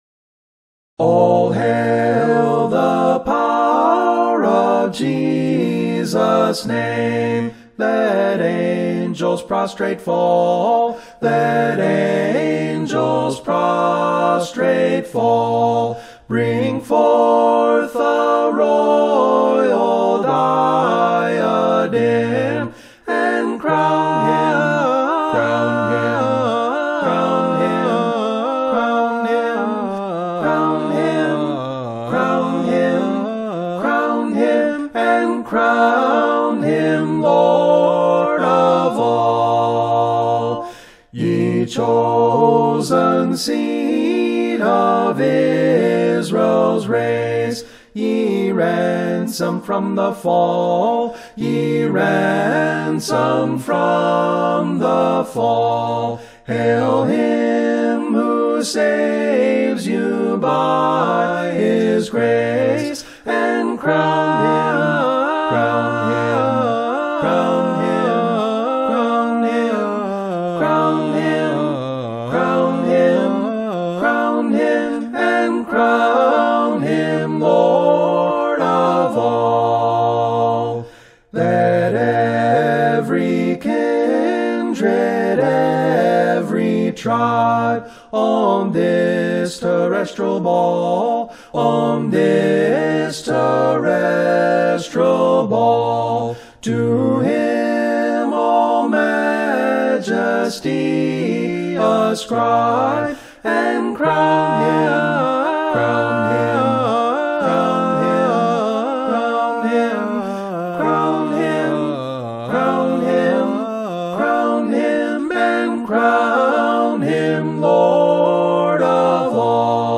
All Hail the Power of Jesus Name - Acapella.mp3